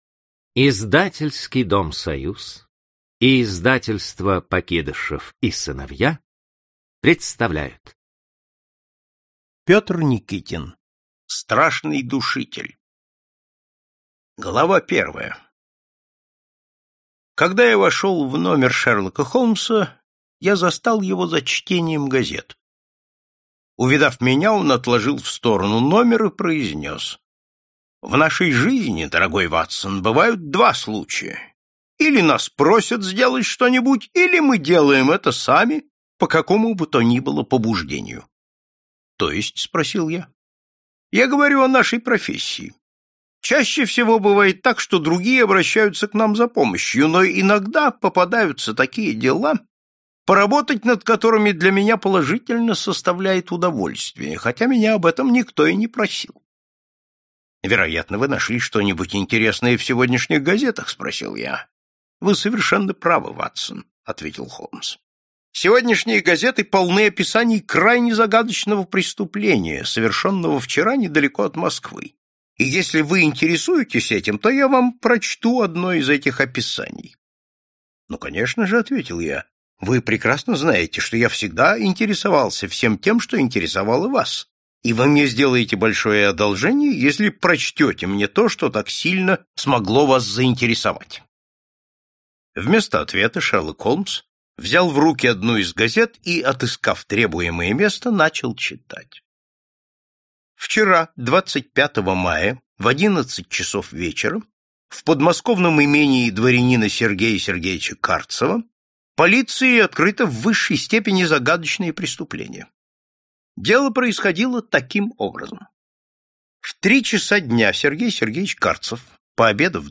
Aудиокнига Страшный душитель